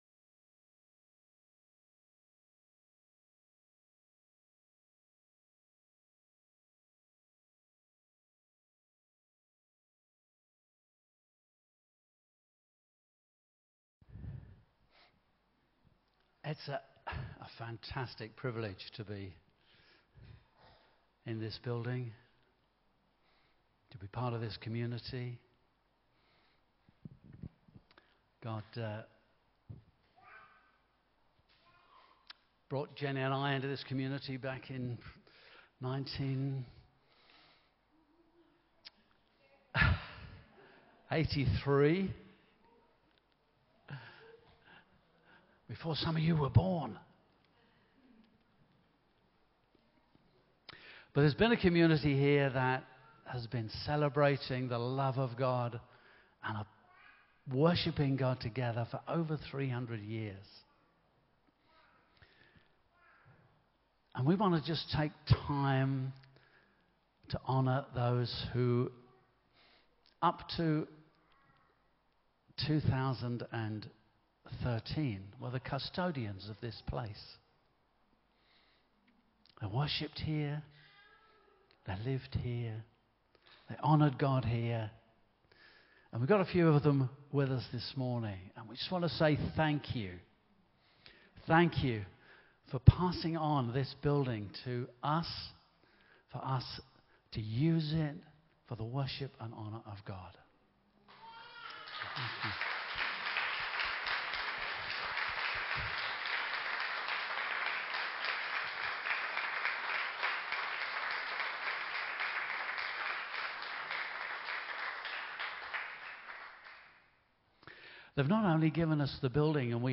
Celebration and Commissioning Service
Sermons